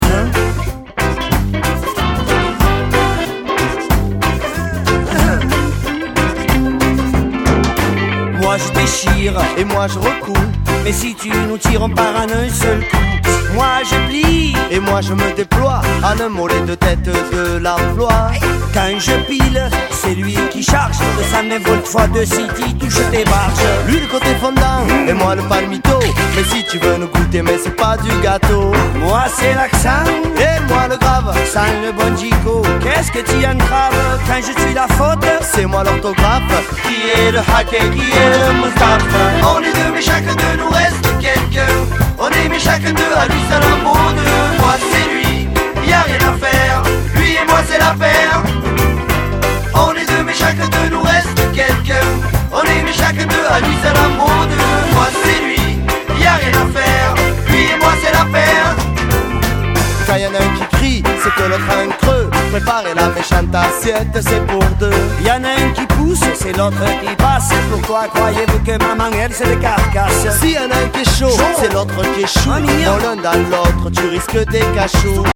WORLD / NEW RELEASE / CD